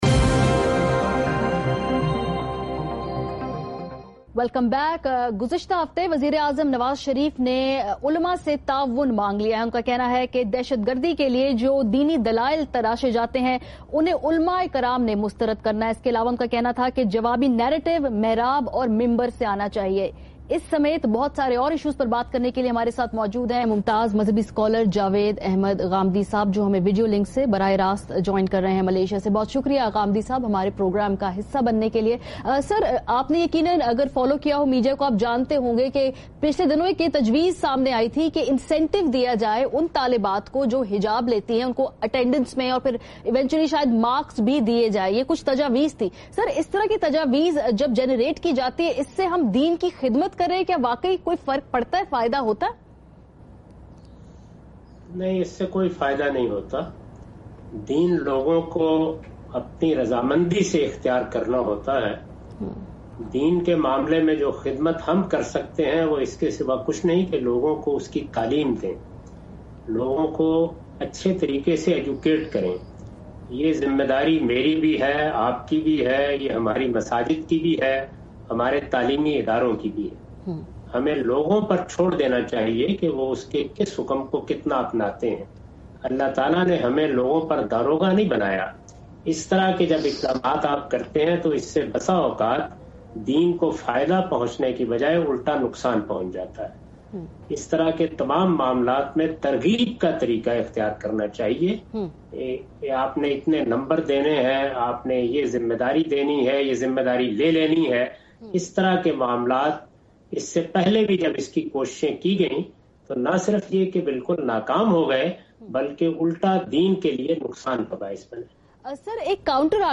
Category: TV Programs / ARY /
In this program Javed Ahmad Ghamidi discusses about "Question about Incentive for Hijab, Ban on Social Media and Counter Narrative" in program "Swal Yeh Hai on ARY News